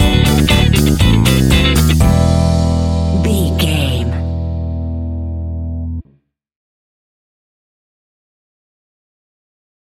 Aeolian/Minor
groovy
uplifting
energetic
drums
bass guitar
electric piano
synthesiser
electric guitar
brass
disco house
upbeat
synth leads
synth bass